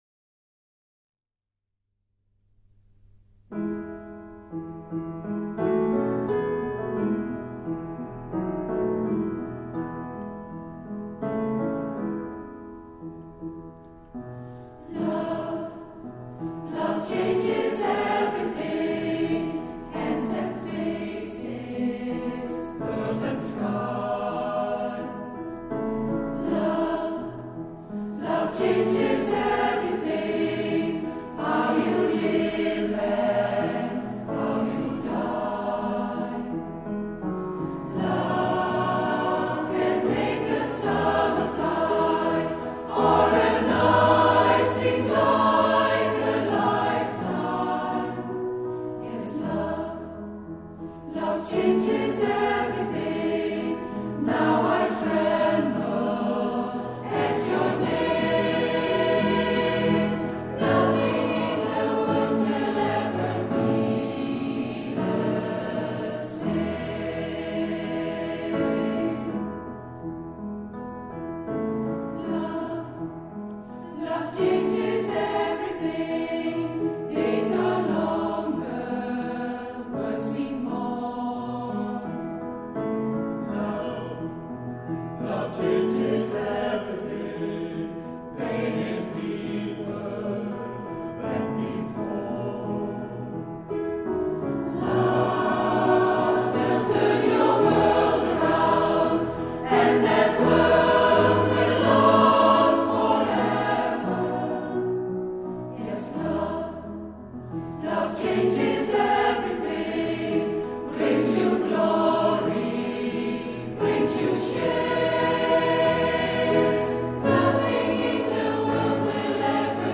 Bijna alle vieringen worden muzikaal ondersteund door een van onze koren.